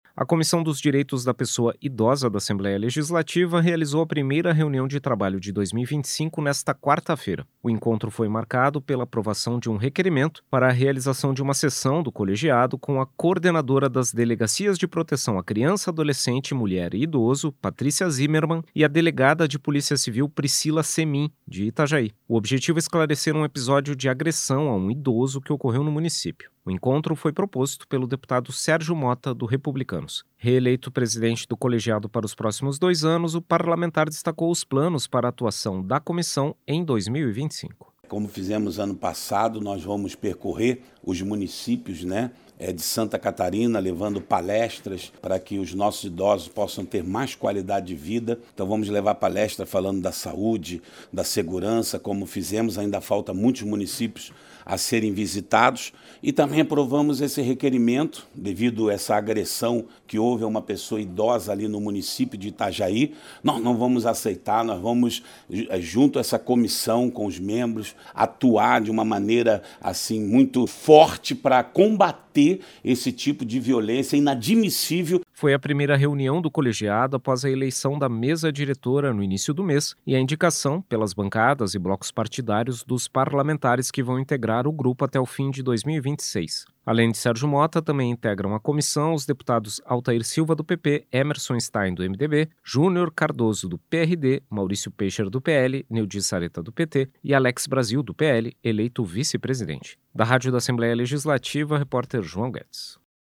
Entrevista com:
- deputado Sergio Motta (Republicanos), presidente da Comissão dos Direitos da Pessoa Idosa da Assembleia Legislativa.